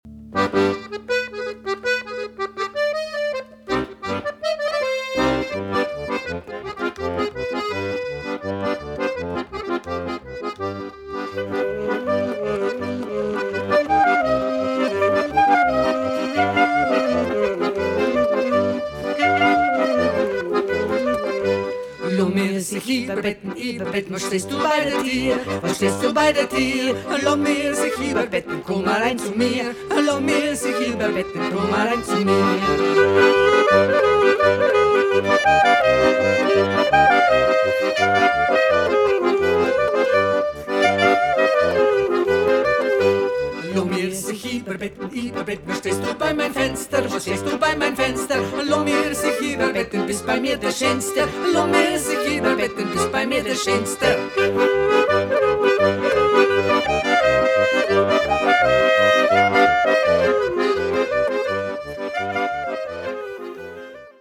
M&T: Traditional Jiddisch
Bassgitarren
Background Gesang